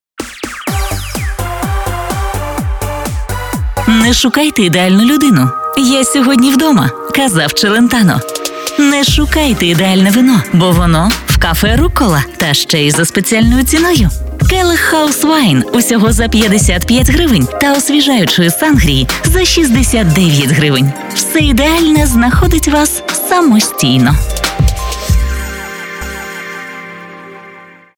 FireBrands – експерти зі звукового дизайну для радіо- і TV-реклами.